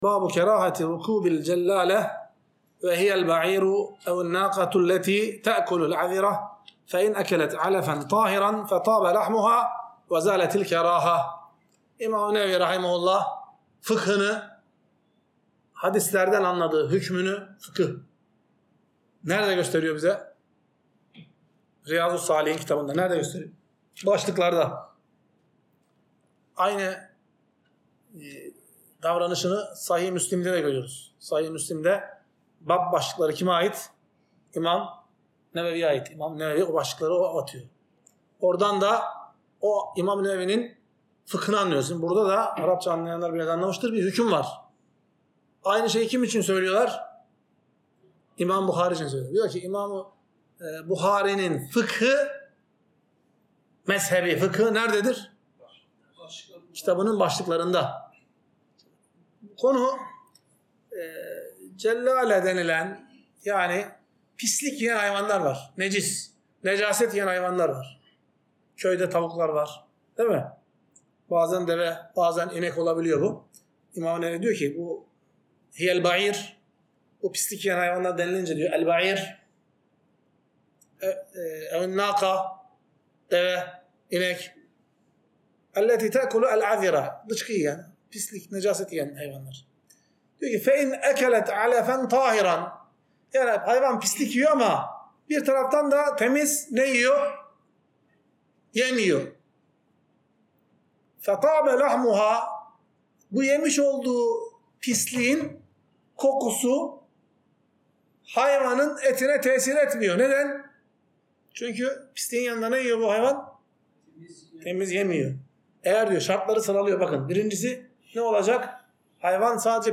Ders - 55.